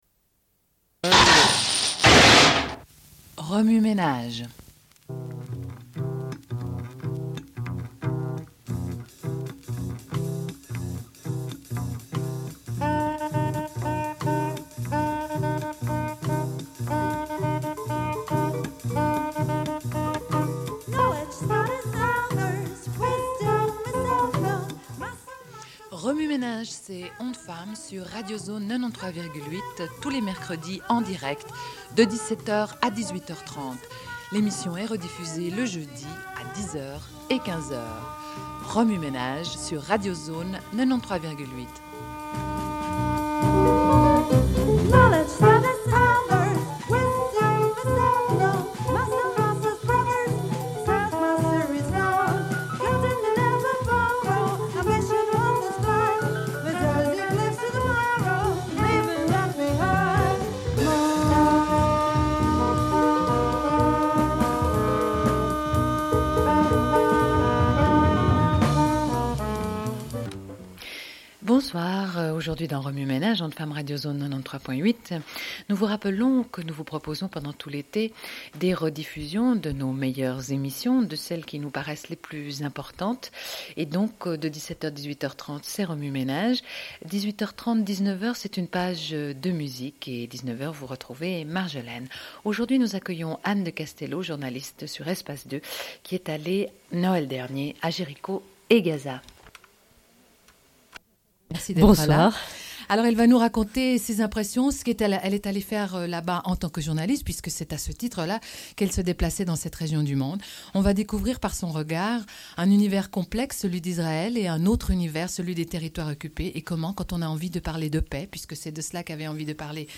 Sommaire de l'émission : en direct